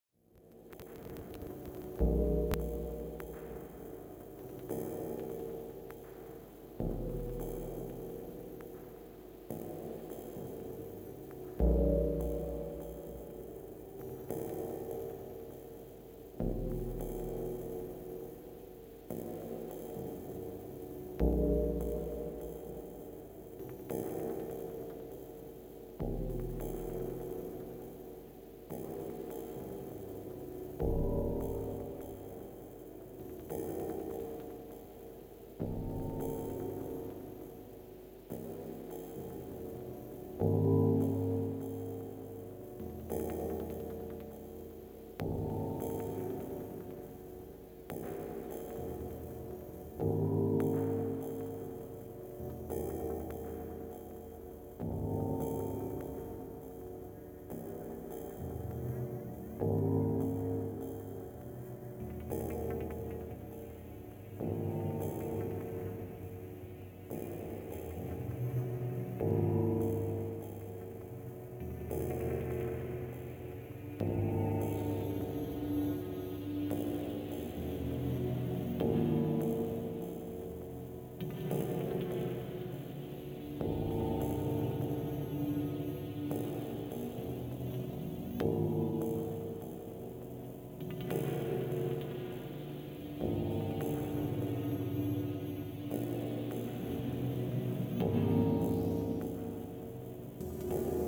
md flambients